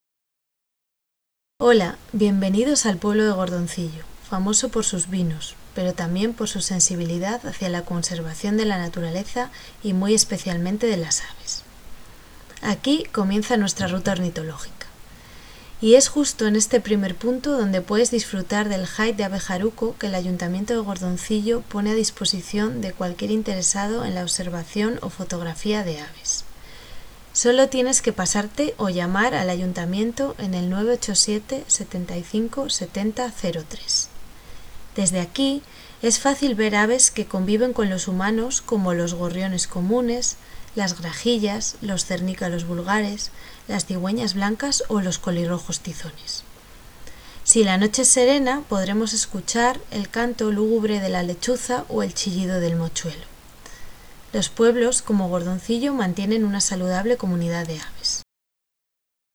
Audio descripción: